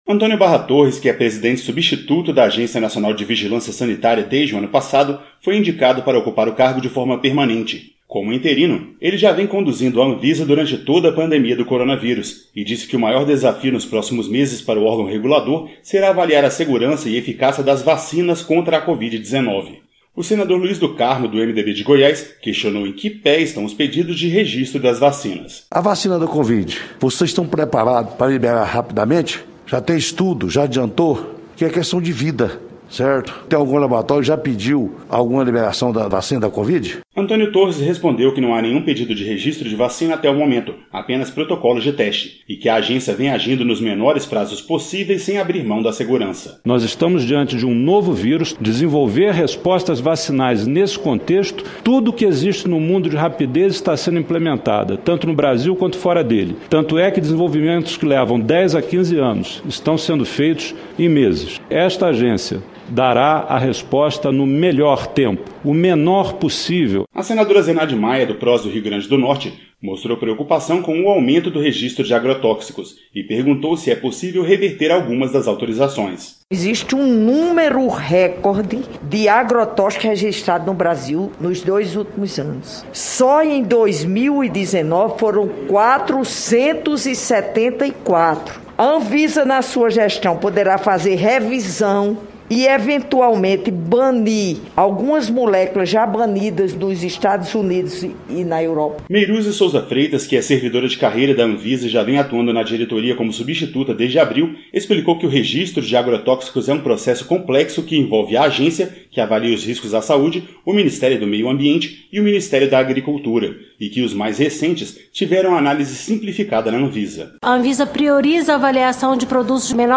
A Comissão de Assuntos Sociais do Senado aprovou quatro indicações para a diretoria da Agência Nacional de Vigilância Sanitária (Anvisa). O nomeado para a Presidência disse que o maior desafio do órgão regulador nos próximos meses será analisar a segurança e a eficácia das vacinas contra o coronavírus. A reportagem